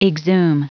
Prononciation du mot exhume en anglais (fichier audio)
exhume.wav